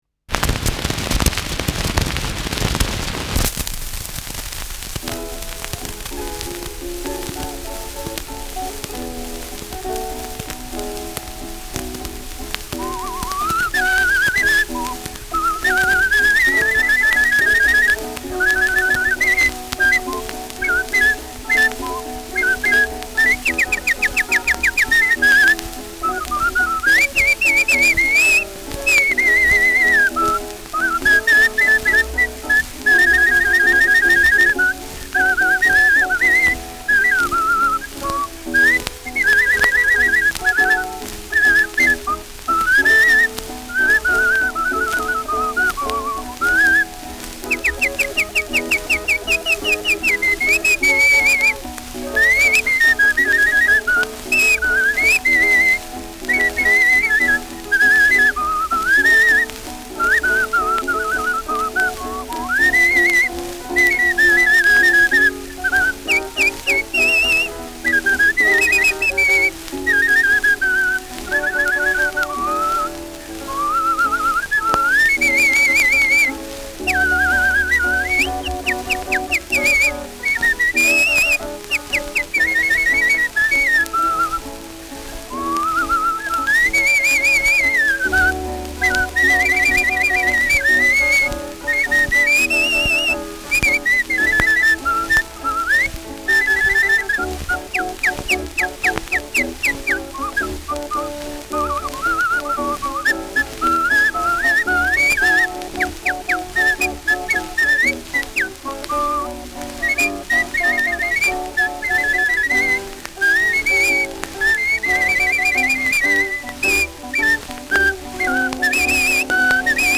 Schellackplatte
Abgespielt : Erhöhtes Grundrauschen : Gelegentlich leichtes Knacken : Nadelgeräusch : Leiern : Teils Klirren
mit eigener Zitherbegleitung